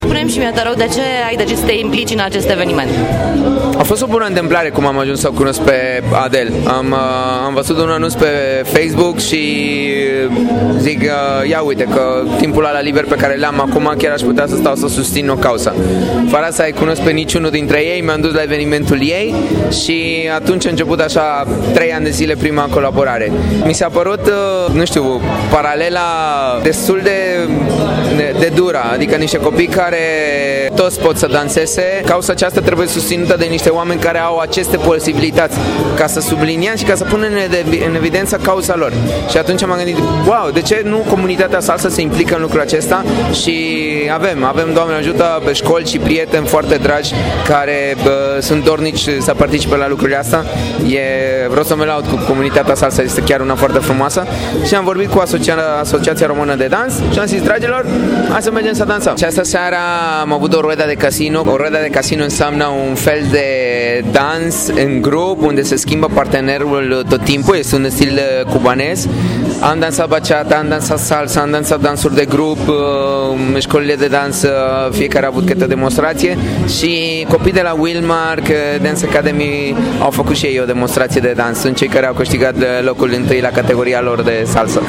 Evenimentul a avut loc in centrul comercial Plaza Romania din Bucuresti, si a reunit apropiati ai bolnavilor de epilepsie si dansatori de salsa de la cele mai importante scoli de profil din Capitala.